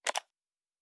pgs/Assets/Audio/Sci-Fi Sounds/Interface/Click 15.wav at master
Click 15.wav